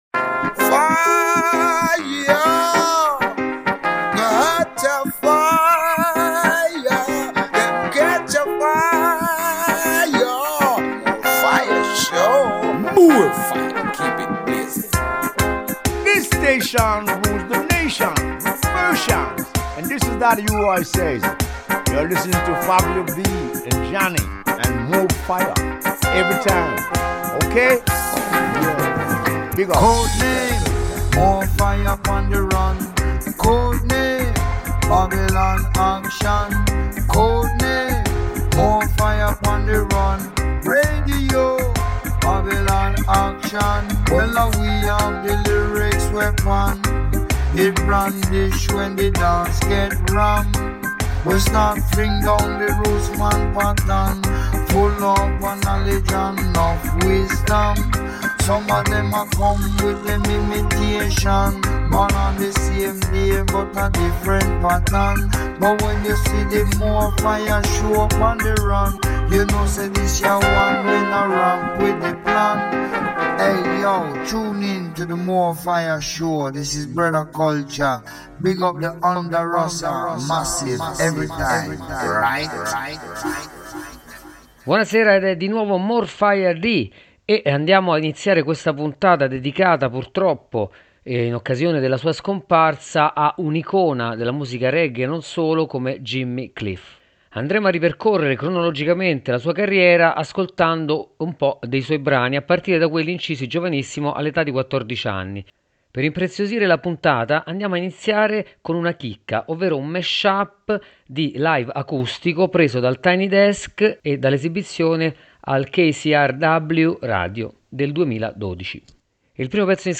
Reggae | Radio Onda Rossa